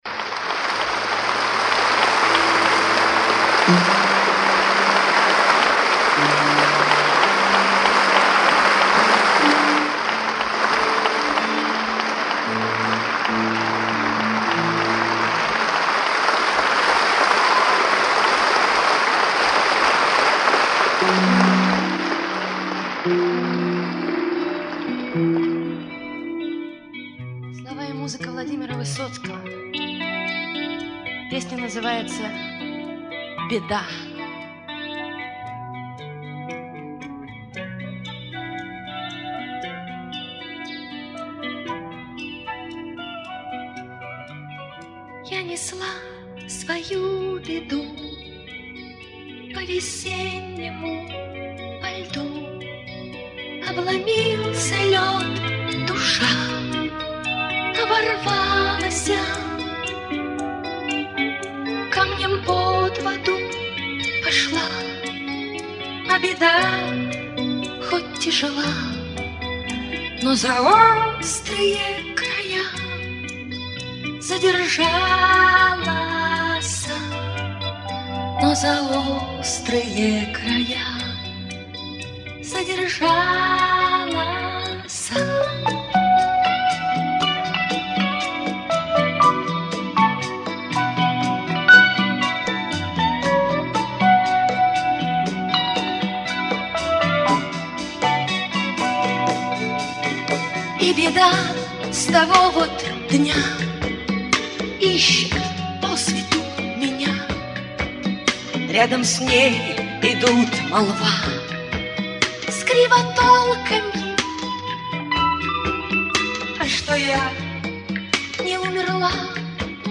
живое исполнение в Сочи 1980